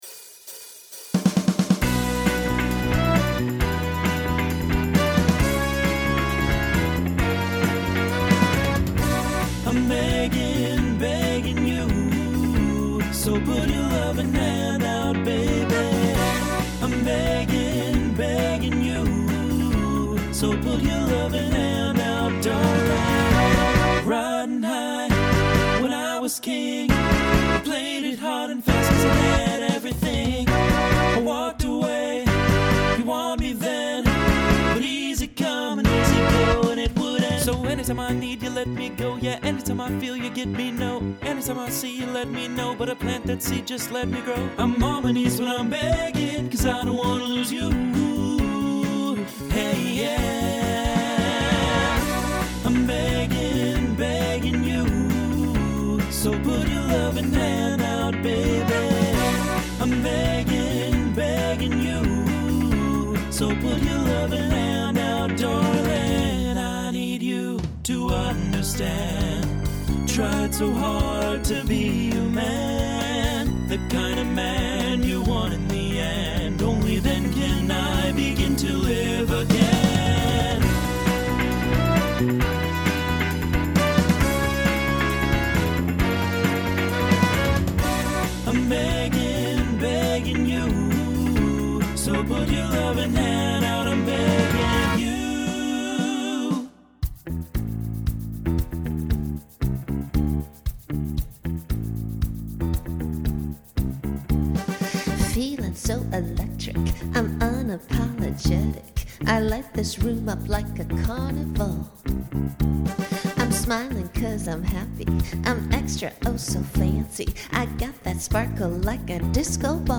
Starts TTB, then SSA, then SATB.
2020s Genre Pop/Dance
Transition Voicing Mixed